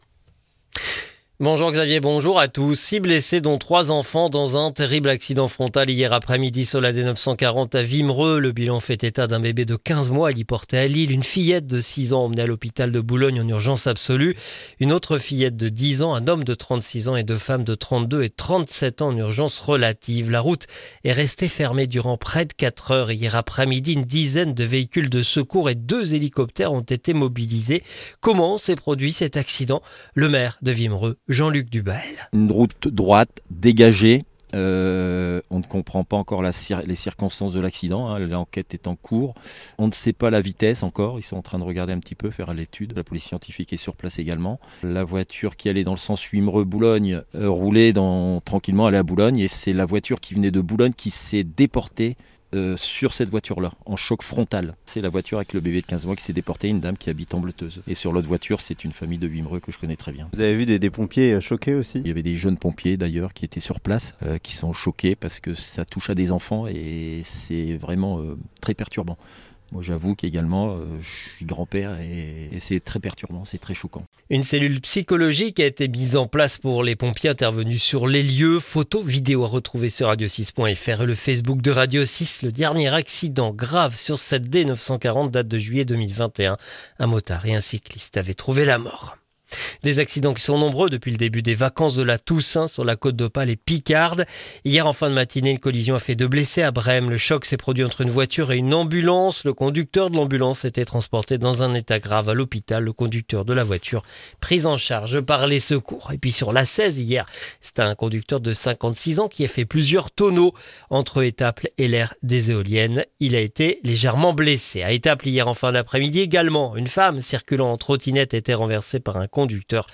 Le journal de vendredi 31 octobre 2025